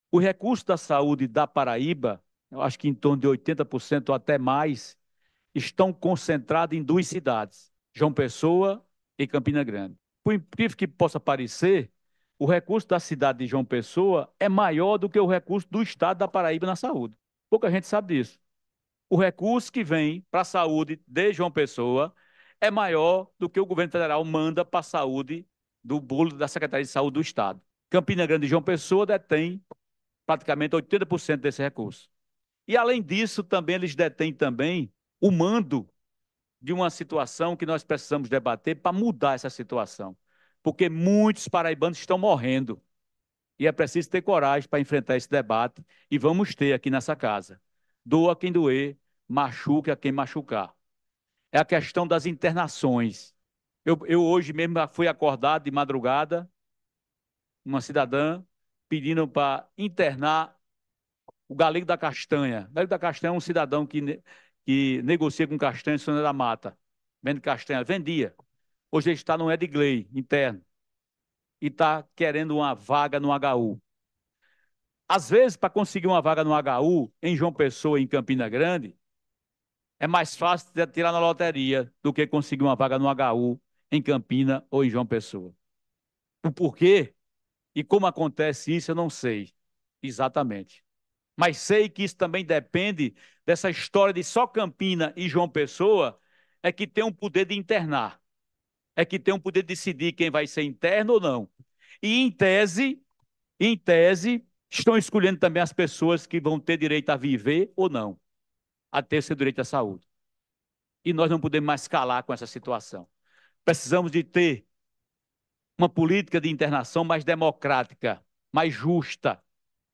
Na última quarta-feira (03), durante a audiência pública realizada pela Assembleia Legislativa da Paraíba, o presidente Adriano Galdino (Republicanos) trouxe à tona uma questão crucial: a concentração desproporcional dos recursos da saúde em duas cidades do Estado, Campina Grande e João Pessoa.
Áudio: Deputado Adriano Galdino